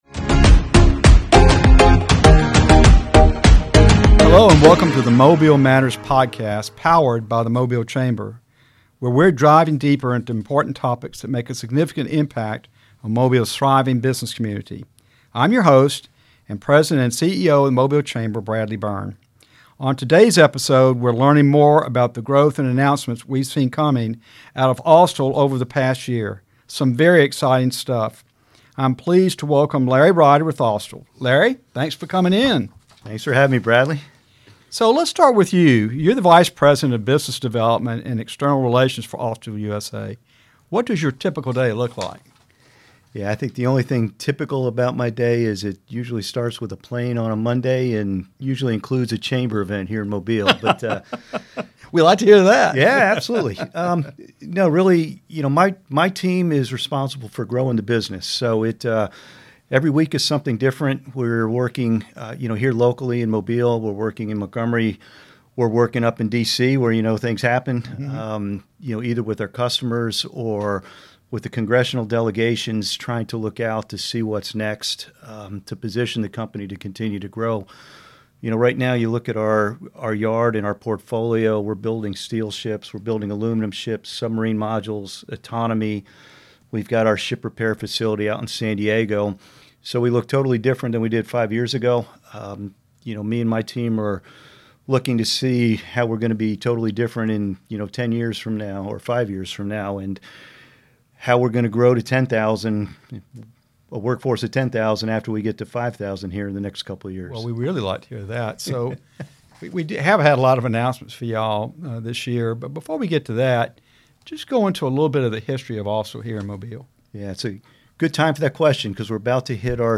Episode 8 Join us for an exciting episode of the Mobile Matters Podcast, hosted by Bradley Byrne, President & CEO of the Mobile Chamber! In this episode, we’re diving into the remarkable growth and innovation at Austal USA over the past year.